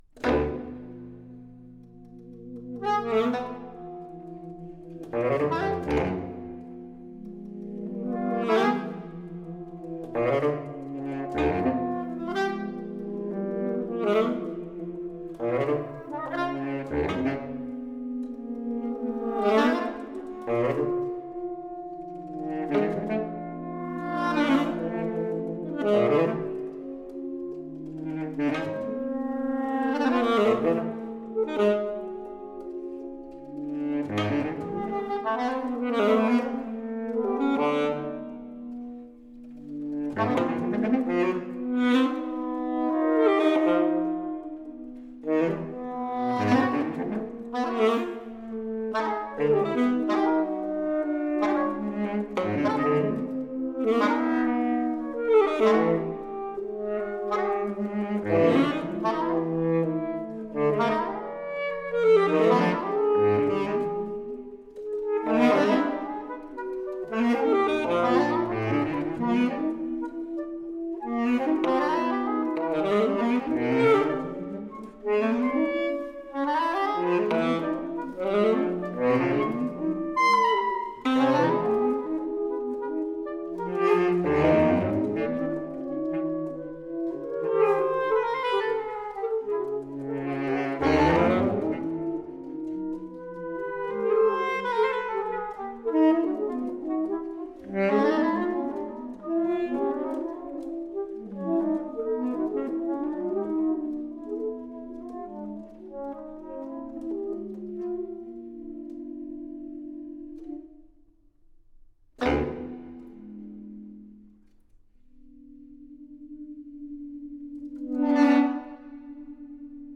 For saxophone quartet.
Five 2-minute movements.